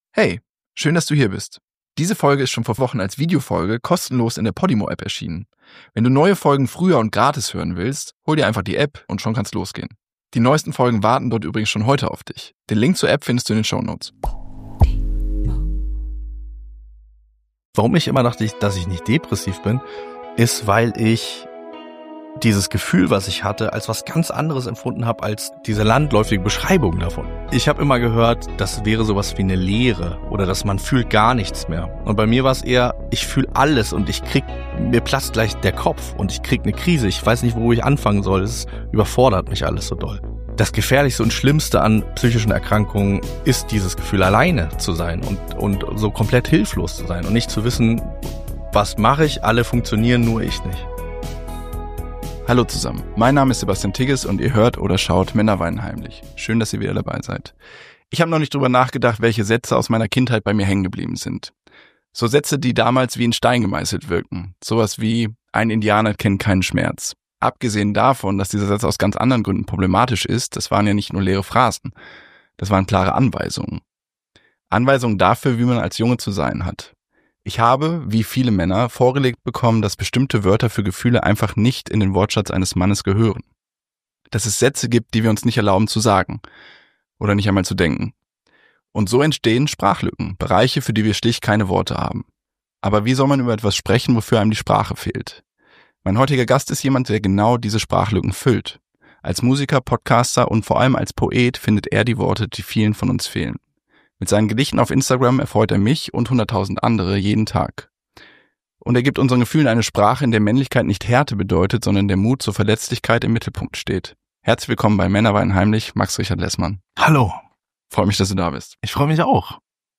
Ein Gespräch über das Anderssein, über Verletzlichkeit und darüber, den Mut zu finden, Gefühle auszusprechen, die lange verboten schienen.